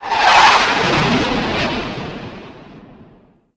sounds_groundwar.dat
1 channel
RocketV1-3.wav